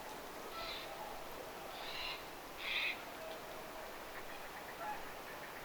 närhi ei tykkää pöllöistä
narhi_siella_on_pollo_rantametsassa_tai_jotain.mp3